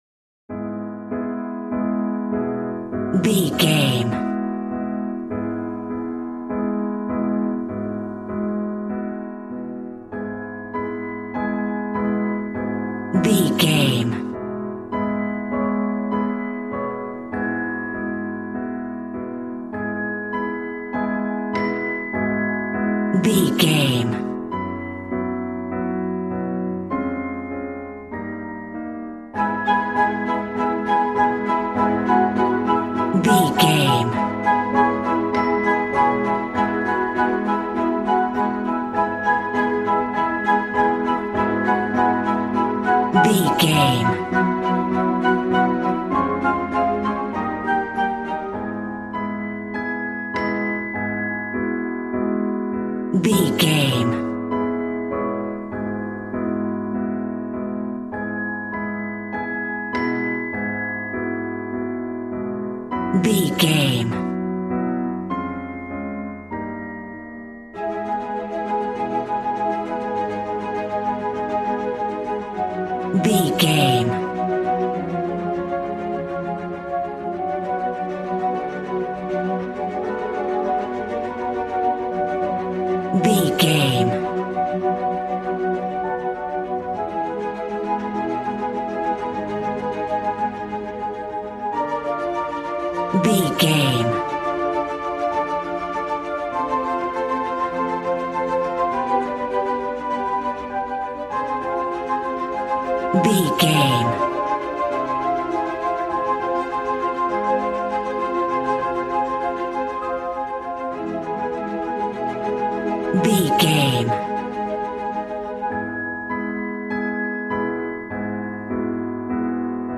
Regal and romantic, a classy piece of classical music.
Aeolian/Minor
A♭
regal
strings
violin
brass